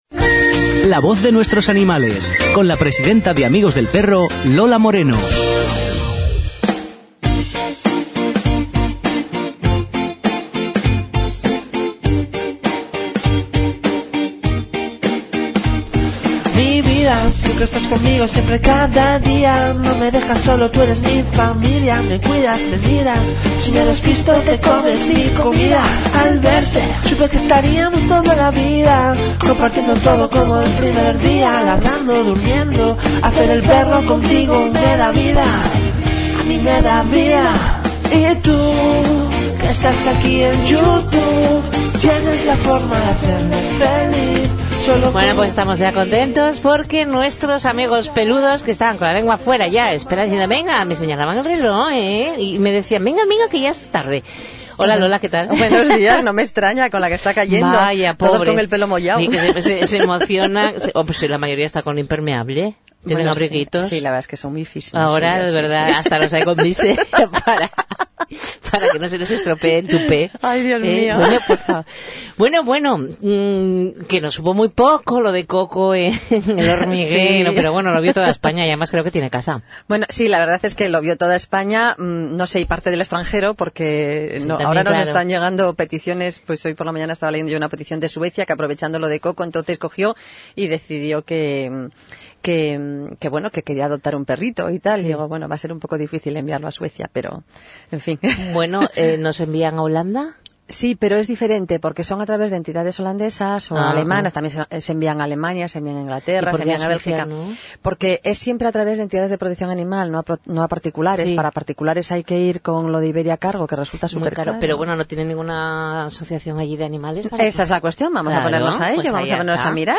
Entrevista en RTA, la radio del Principado de Asturias
Aquí os dejo la entrevista, con ganas de poder estar por estas bonitas tierras del norte de España pronto, transmitiendo como siempre a los adoptantes y todos aquellos que quieran acercarse, la maravillosa experiencia de educar con respeto, cariño y mucho amor a nuestros peludos.